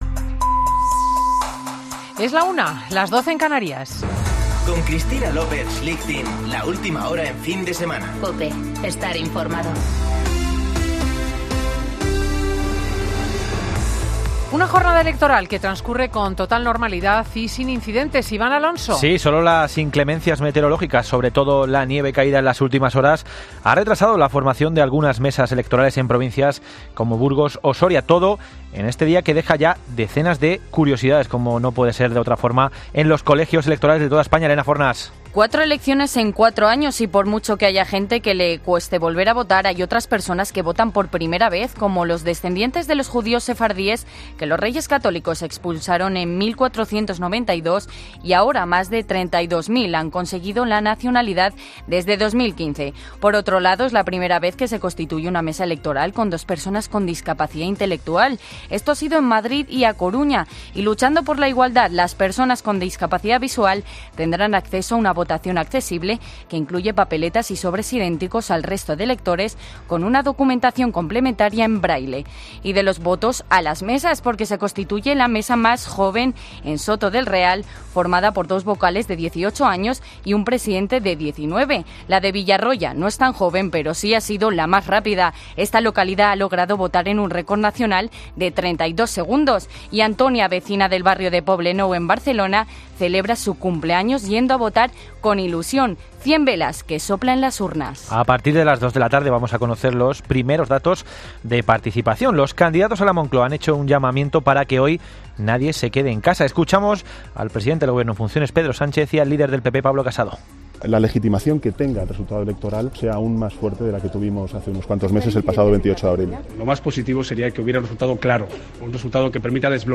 Boletín de noticias COPE del 10 de noviembre de 2019 a las 13.00 horas